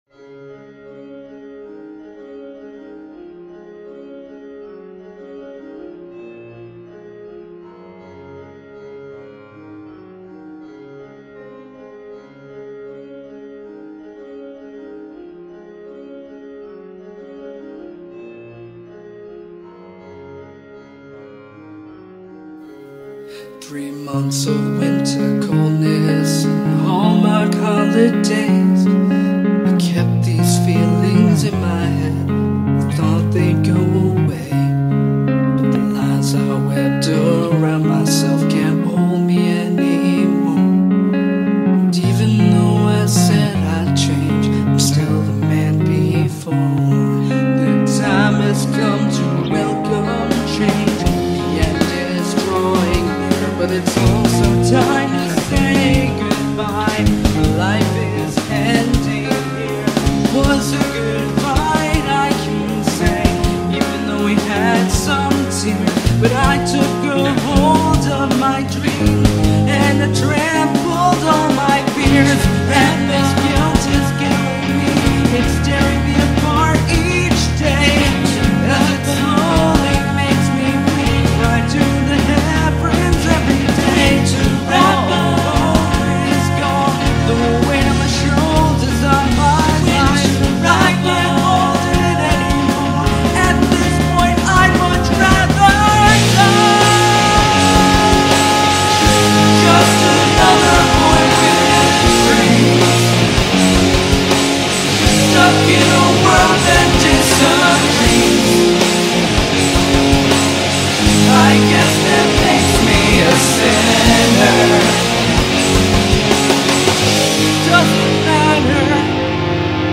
Metal/Rock